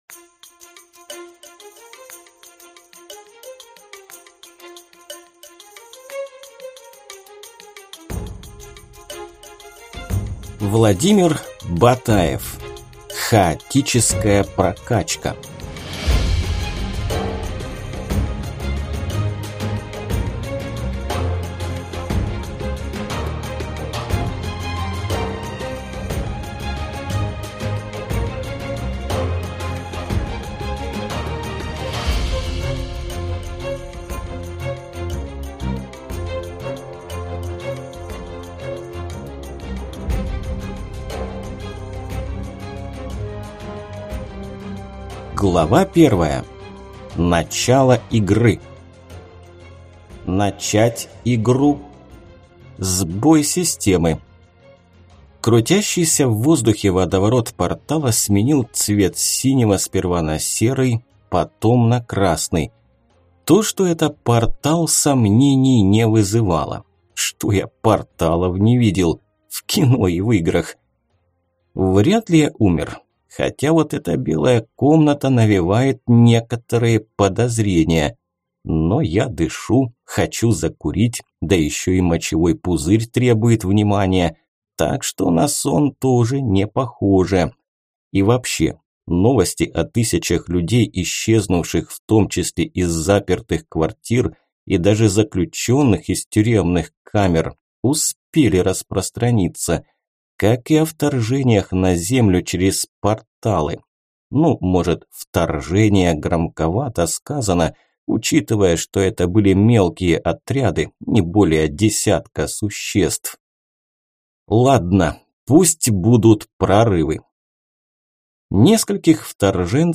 Аудиокнига Хаотическая прокачка | Библиотека аудиокниг
Прослушать и бесплатно скачать фрагмент аудиокниги